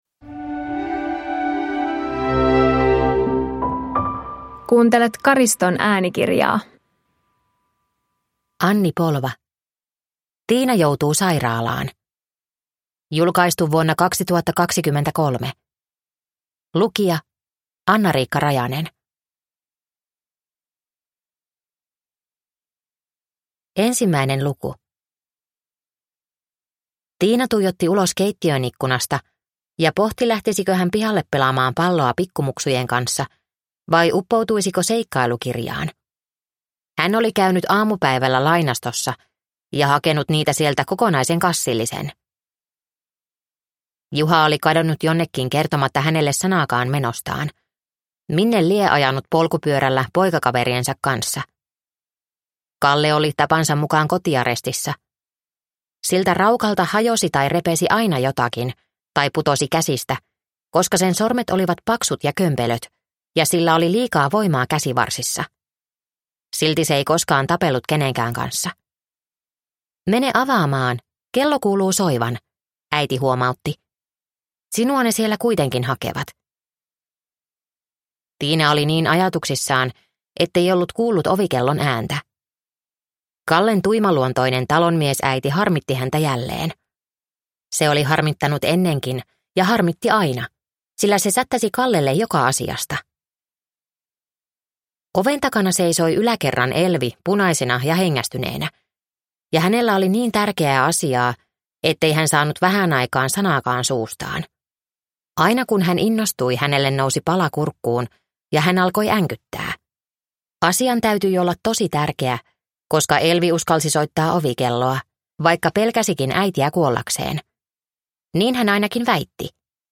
Tiina joutuu sairaalaan (ljudbok) av Anni Polva